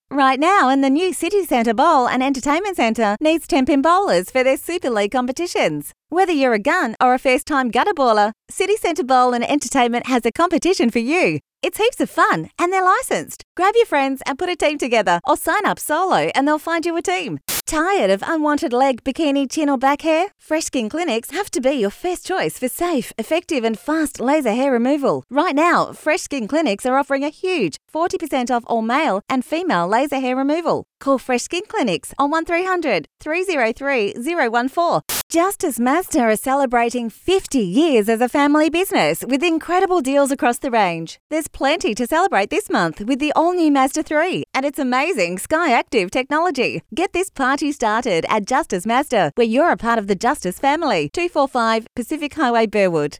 • Hip Young Cool
• Versatile
• Charismatic